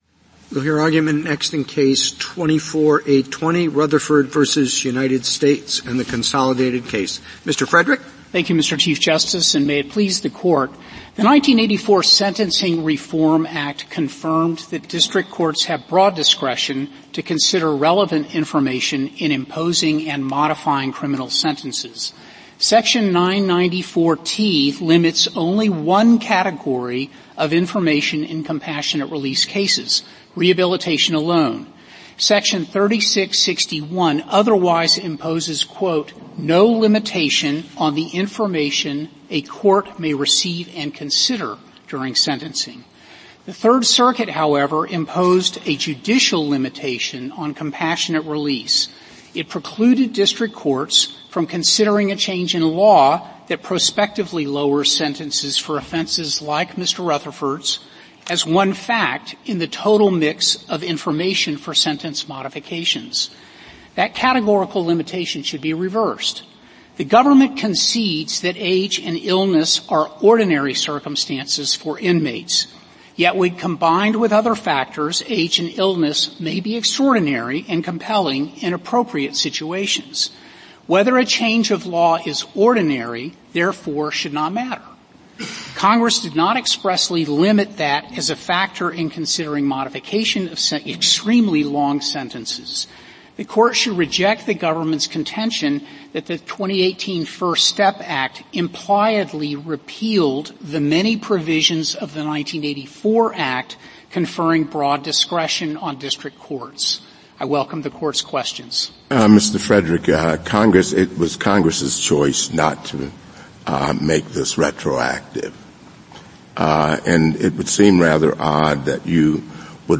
Supreme Court Oral Arguments · S2025